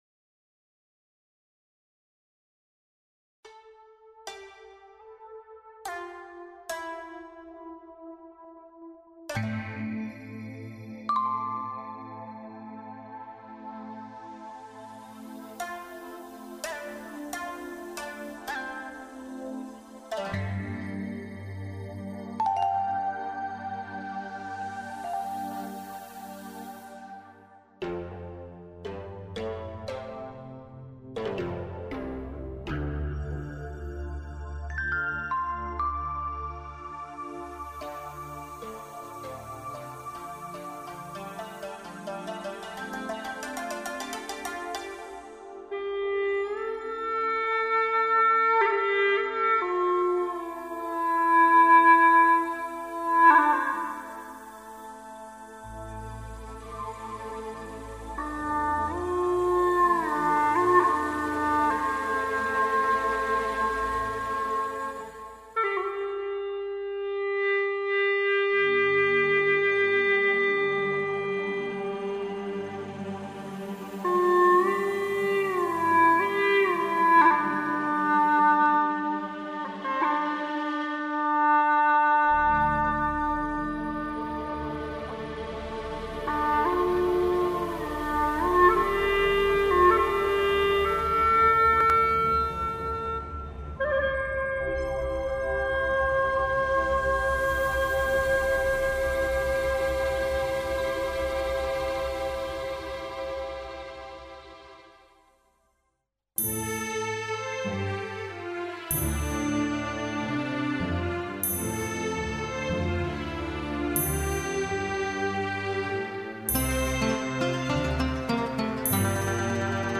调式 : F 曲类 : 流行 此曲暂无教学 点击下载 月朦胧，水悠悠，孤雁立桥头。